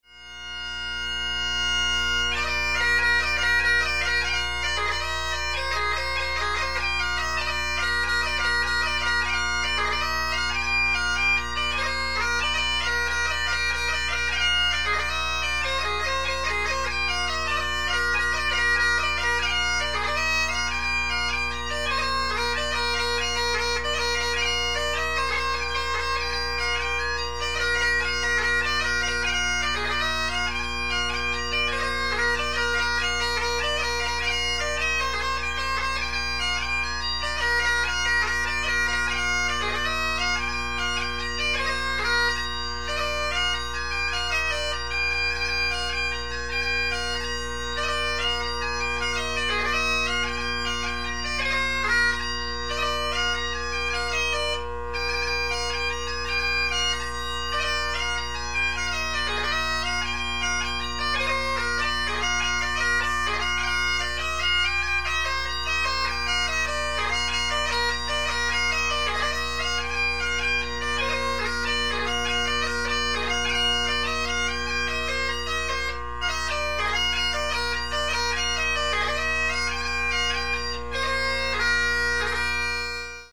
In the interest of efficiency, most of these examples are only the first one or two parts of the tune, but they should be enough to give a feel for my playing.